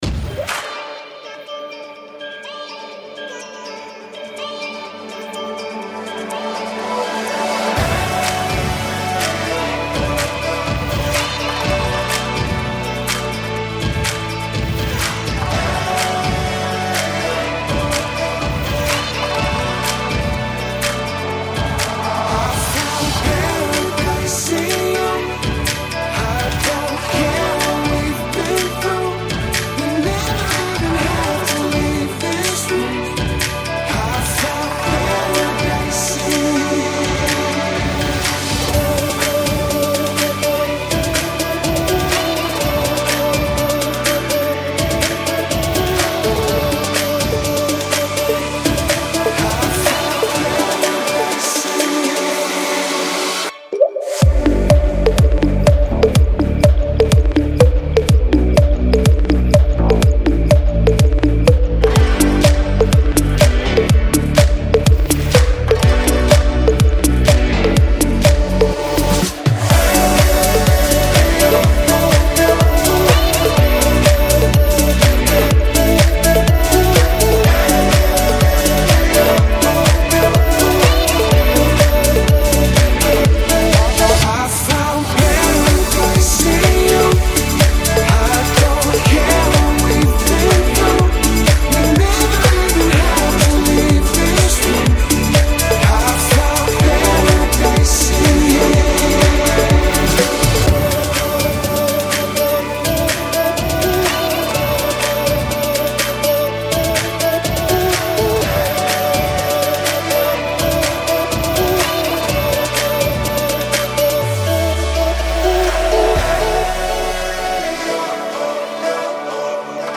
a really cool, tropical re-work